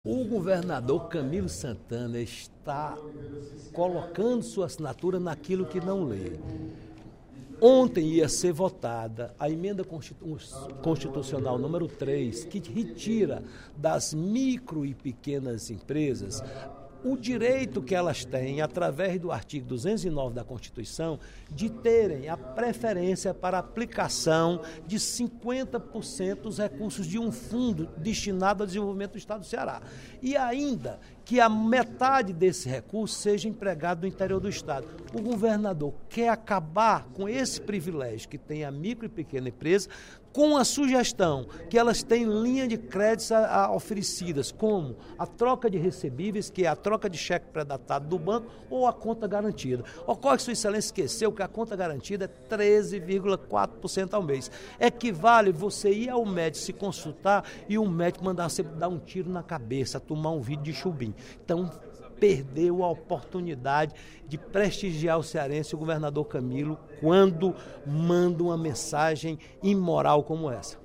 O deputado Roberto Mesquita (PSD) avaliou, durante o primeiro expediente da sessão plenária desta sexta-feira (05/05), que o projeto de emenda constitucional (PEC) nº 3/16, oriundo da mensagem nº 8.107/16, é “o maior absurdo que pode existir”.